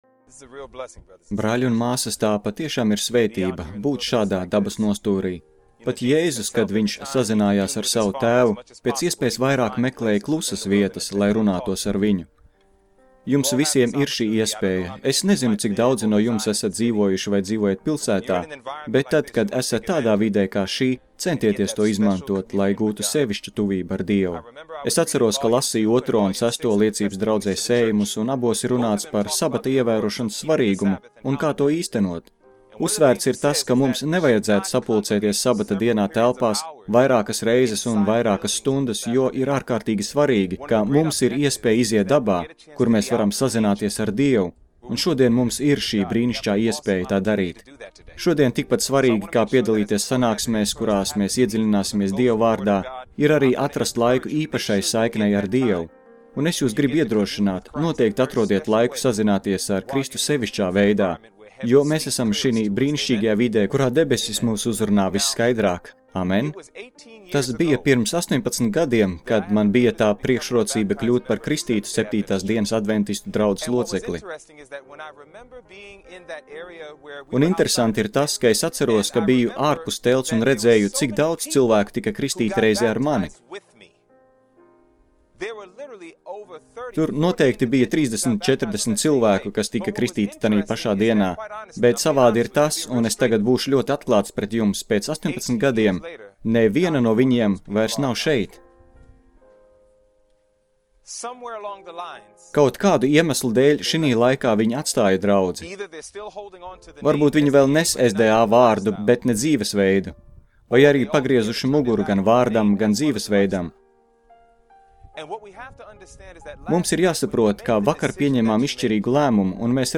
Seminārs